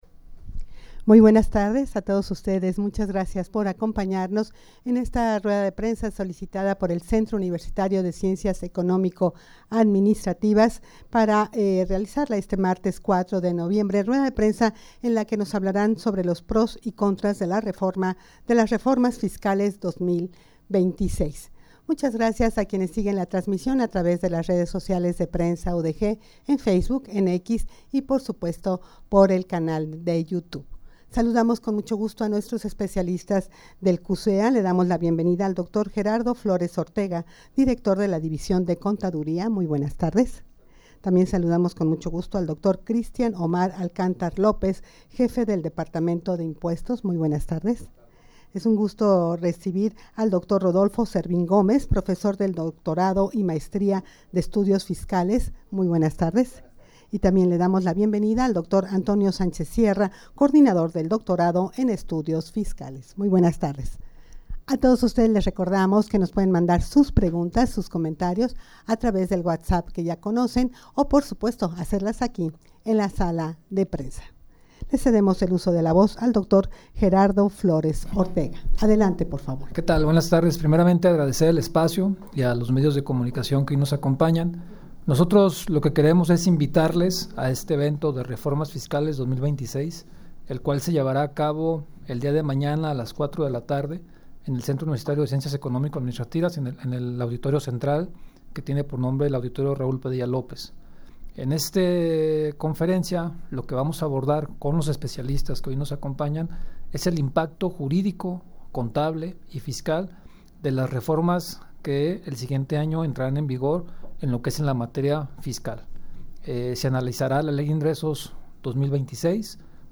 Audio de la Rueda de Prensa
-rueda-de-prensa-pros-y-contras-de-las-reformas-fiscales-2026.mp3